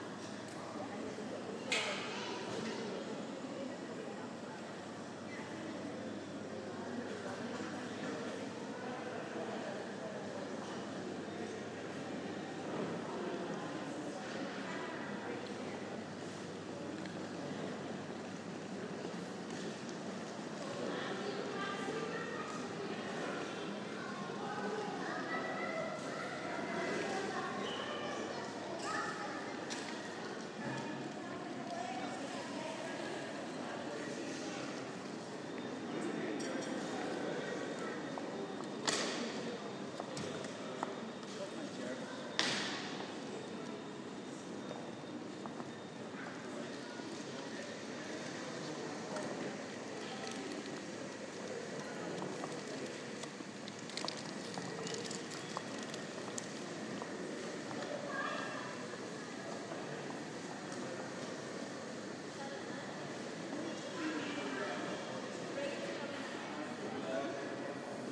Colbert station on a Sunday afternoon
Train station, Limerick, Ireland